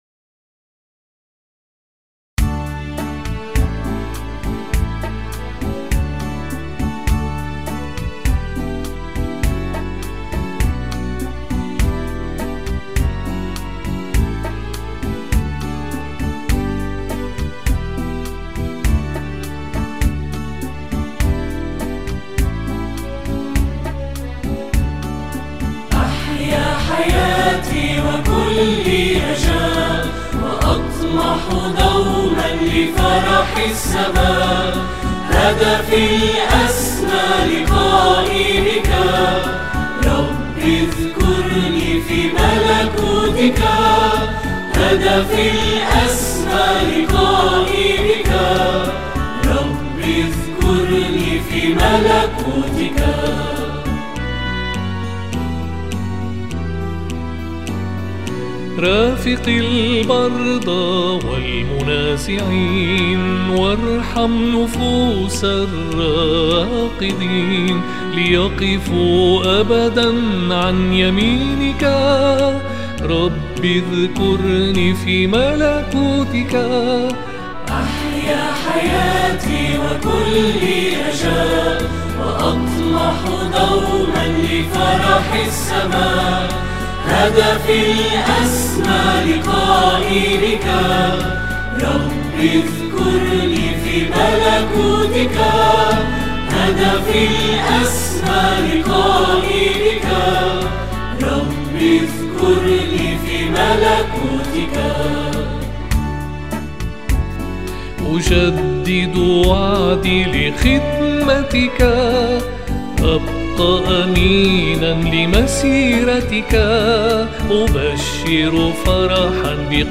ترانيم